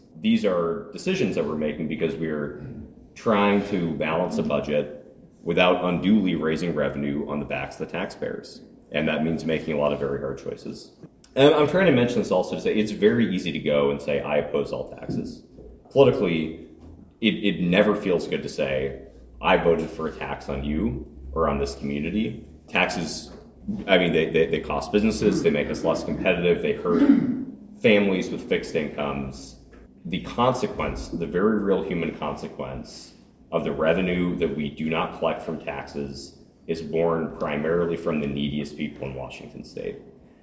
Port Angeles – State Representative Adam Bernbaum of the 24th District was the guest speaker at Wednesday’s Port Angeles Chamber luncheon, presenting his take on how this year’s legislative session went in Olympia.